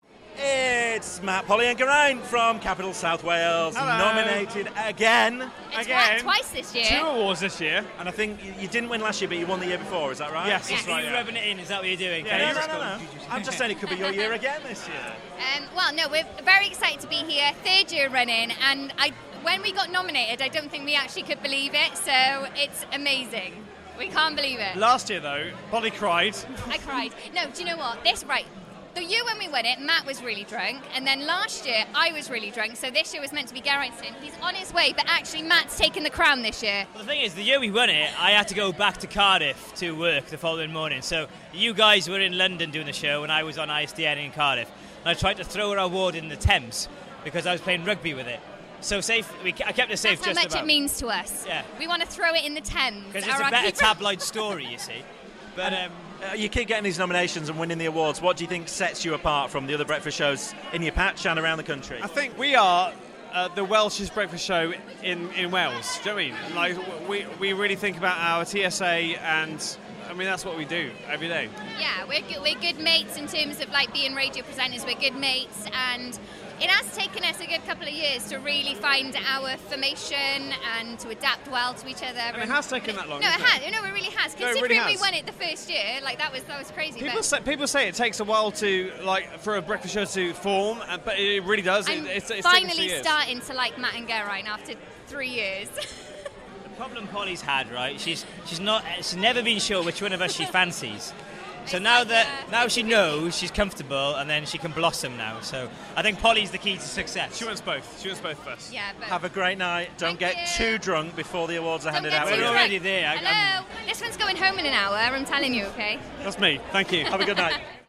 at the Arqiva Commercial Radio Awards 2016.